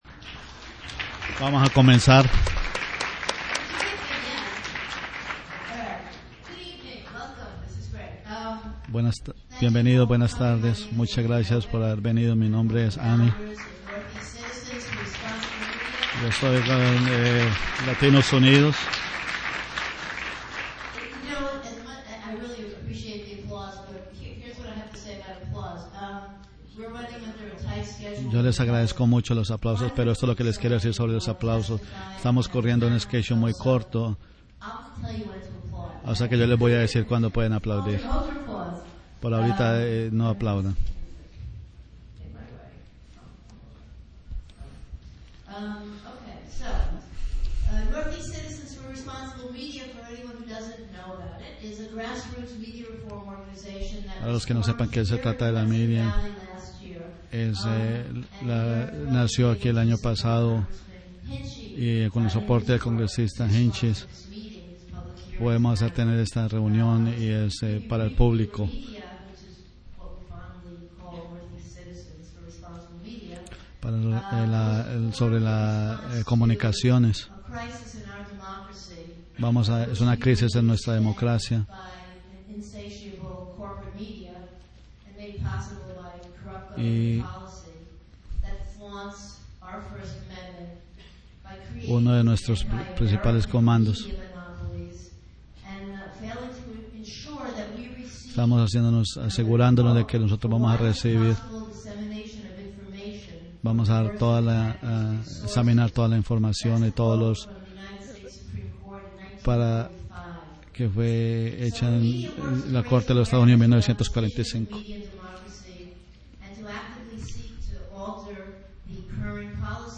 FCC Public Hearing_Spanish Translation_at FDR Center in Hyde Park (Audio)
The FCC is once again taking up the issue of media ownership rules and intends, as it tried unsuccessfully in 2003, to ignore its responsibility to the public. This is a Spanish translation of the audio of the hearing.